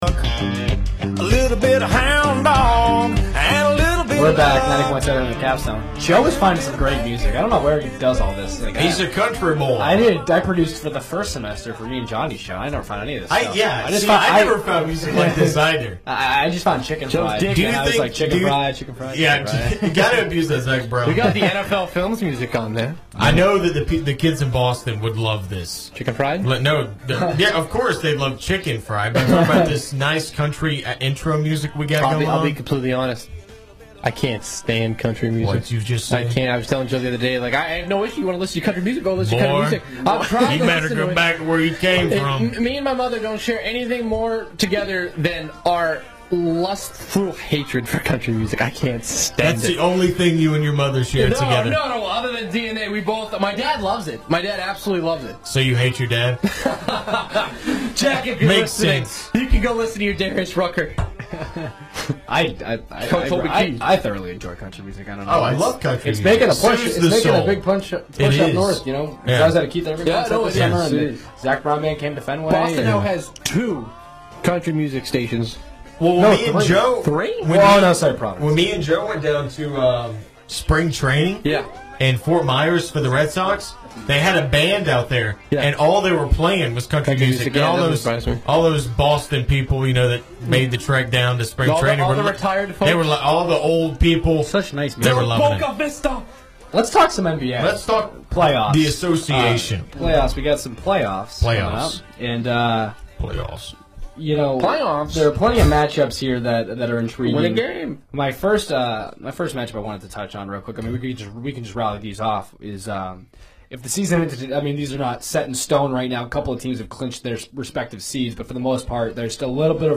WVUA-FM's and Tuscaloosa's longest running sports talk show "The Student Section"